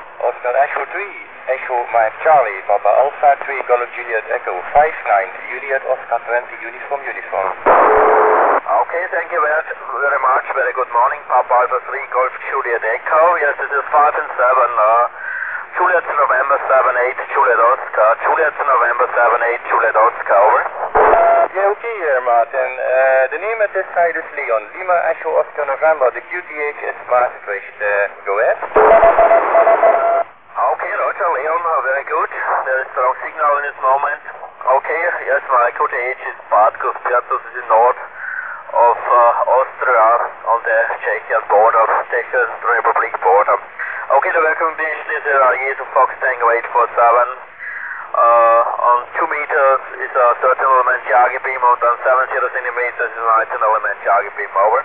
Satellite QSO ( Some very short Audio Fragments concerning Firsts via Satellites )
Remark : the taperecorder had been connected ( directly ) to the Alinco tranceiver DR 510E ( concerning UO-14 and AO-27 in mode J ).
Apologies because of the audio quality.
Further, the taperecorder was very noisy. Because of the compression, most ( mono ) audio files ( sample rate = 6000, resolution = 8 bits ) delivered a bad signal noise ratio.